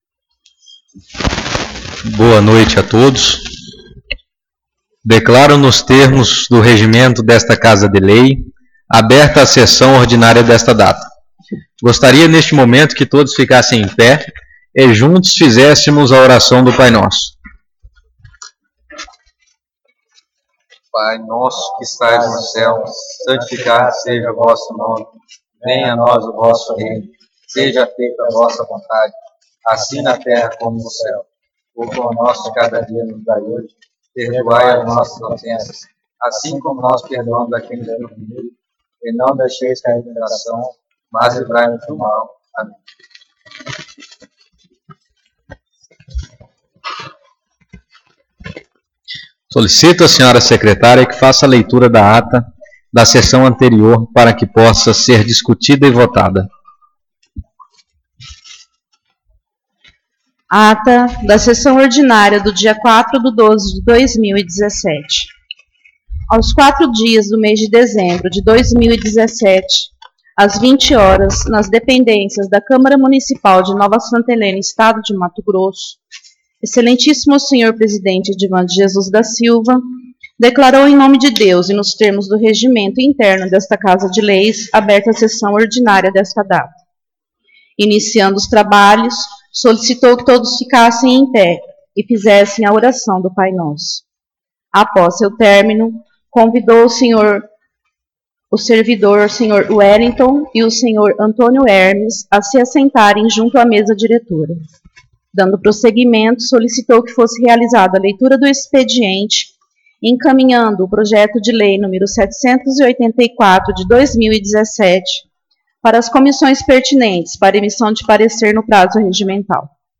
Sessão Ordinária 11/12/2017